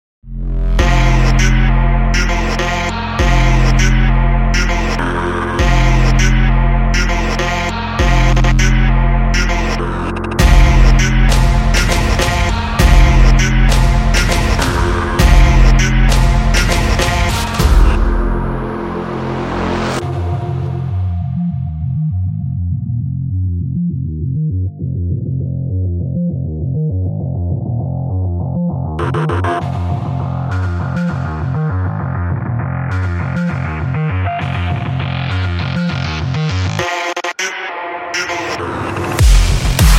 мощные
качающие